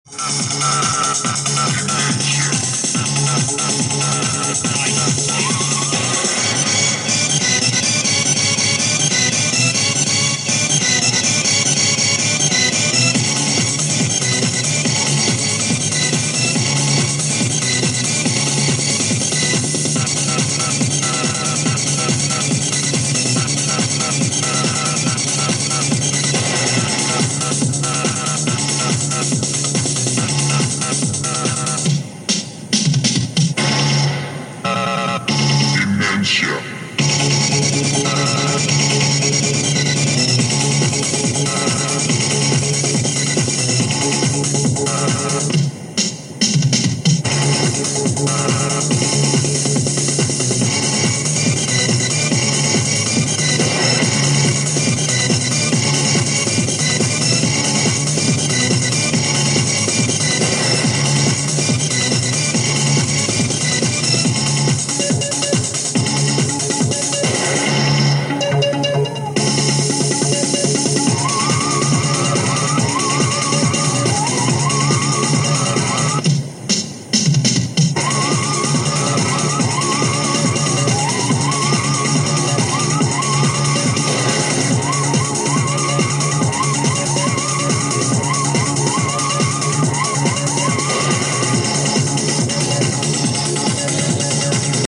Zná někdo tyto Rave hudební skladby?